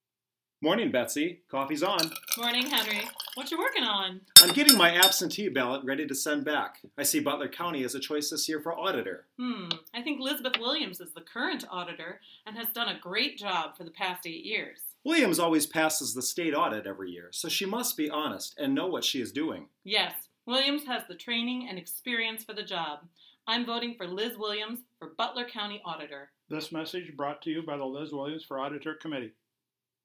radio ad #1